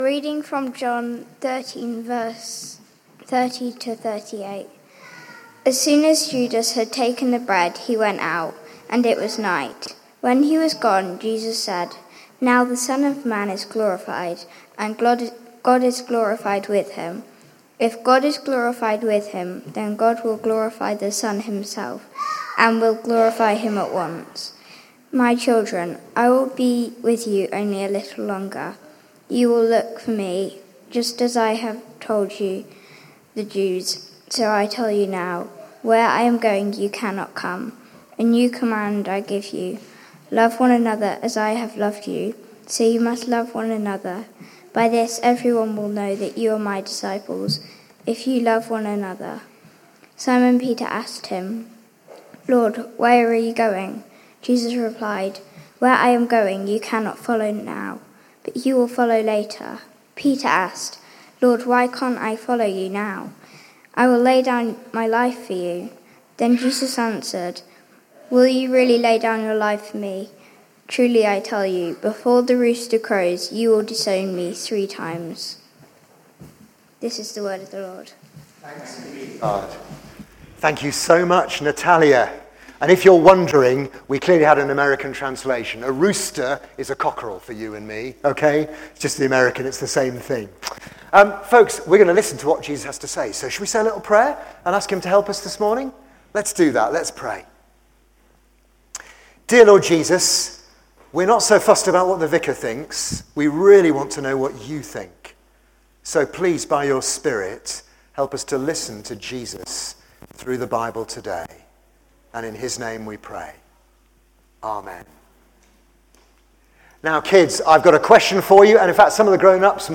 1. The Love – Our new sermon series on John